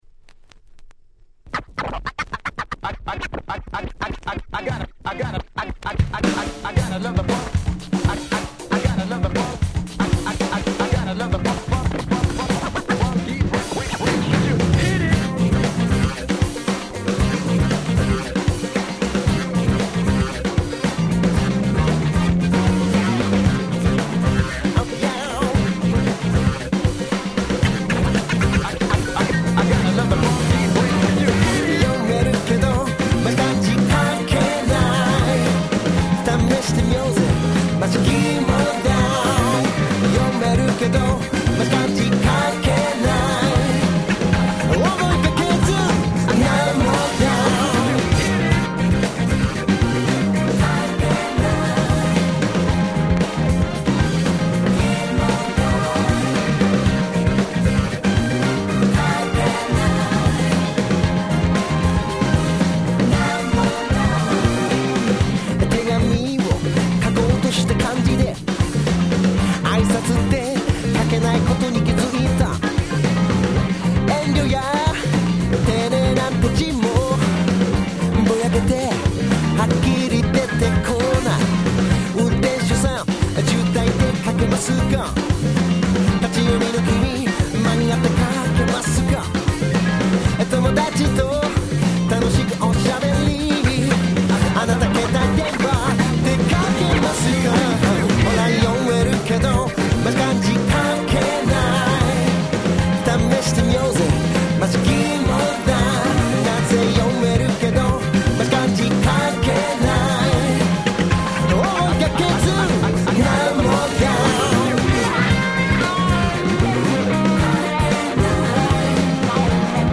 Dance Classicsの往年の名曲達を面白楽しく日本語で替え歌してしまった非常にユーモア溢れるシリーズ！(笑)